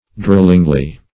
drollingly - definition of drollingly - synonyms, pronunciation, spelling from Free Dictionary Search Result for " drollingly" : The Collaborative International Dictionary of English v.0.48: Drollingly \Droll"ing*ly\, adv.
drollingly.mp3